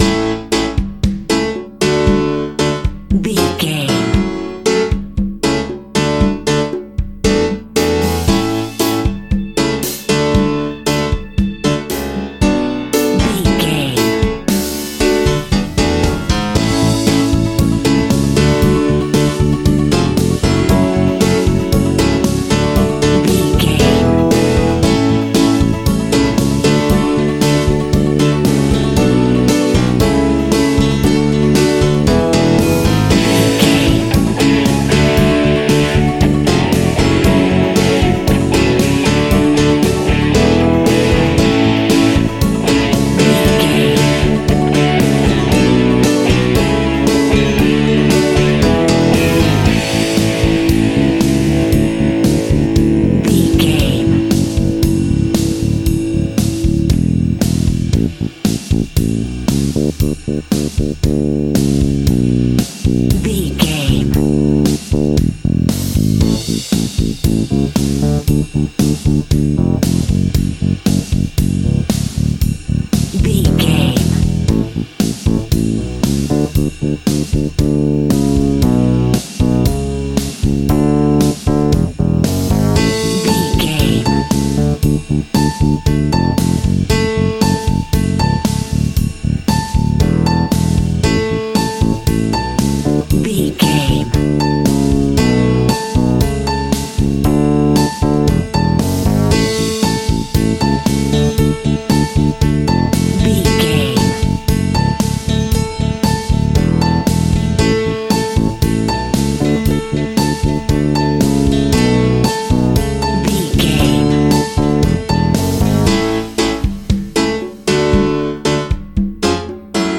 Ionian/Major
indie pop rock music
indie pop rock instrumentals
upbeat
uptempo
rocking
groovy
guitars
bass
drums
piano
organ